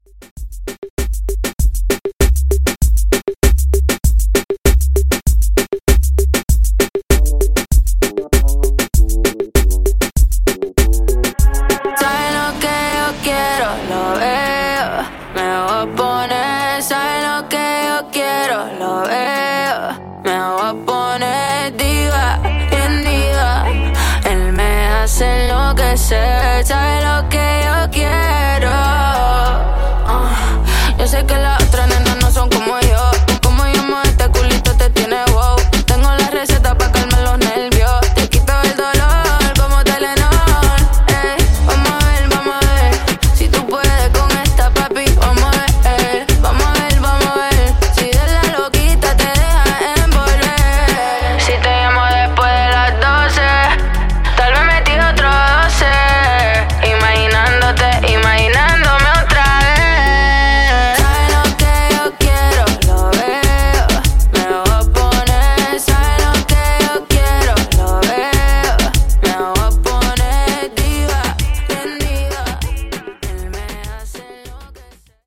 Reggaeton)Date Added